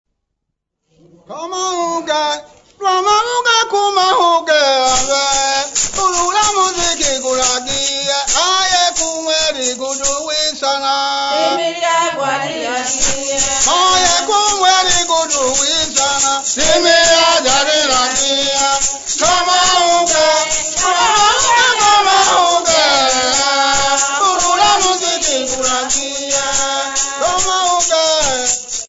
Folk music
Field recordings
sound recording-musical
The song was recorded in Dar-es-Salaam, but their origin is up country near Tabora in the home district of the Nyamwezi tribe. Uswezi divination song, with gourd rattles.
96000Hz 24Bit Stereo